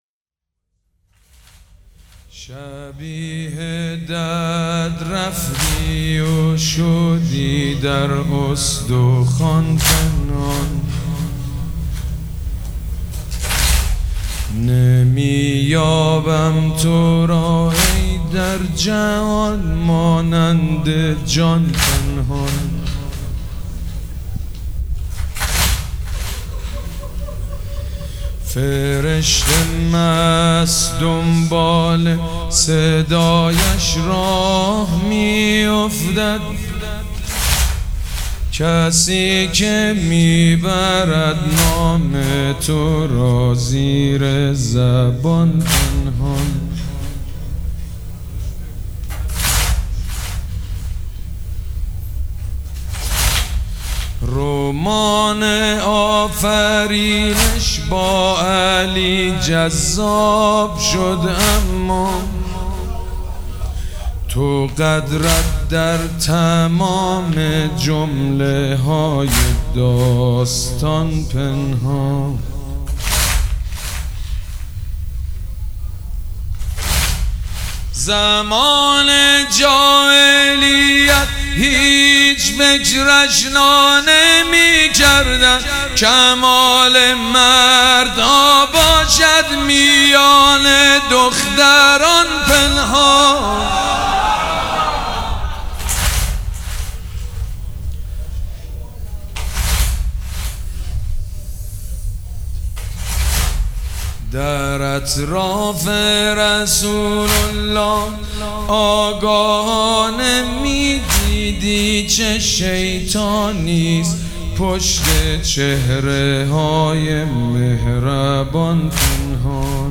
مداح
مراسم عزاداری شب شهادت حضرت زهرا (س)